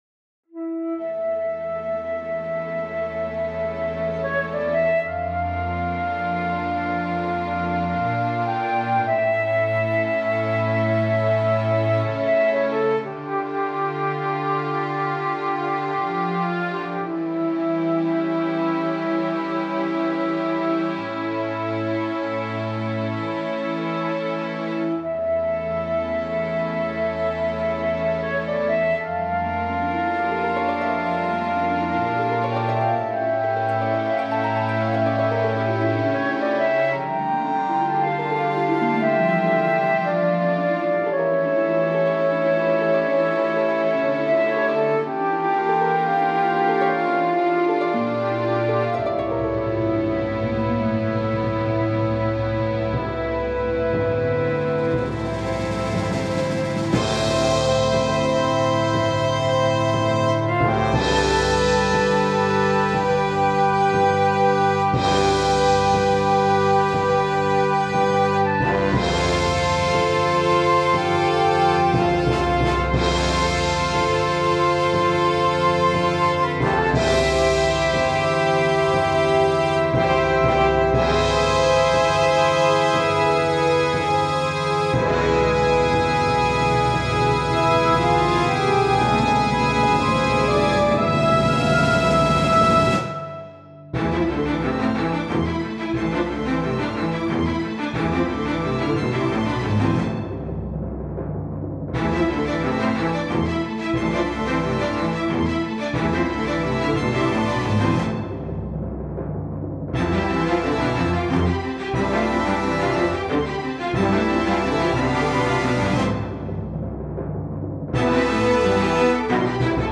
3/ La cascade des géants : Pas mal de boulot ici, on change de climats, c'est convaincant. Le mix souffre par contre d'un manque de dimensions, c'est dommage, ça aurait pu être beaucoup plus impressionnant.
Ici les nuances des cordes sont trop statiques, et la flûte et trop forte.
Mais la harpe fait très bien le job.
La suite avec les cuivres, est un peu moins bonne.
Deuxième partie : ça s'acélère, c'est parfait.
Pb de mix, néanmoins, surtout pour l'équilibre des cordes.
Coda avec rappel du début. Tout se calme.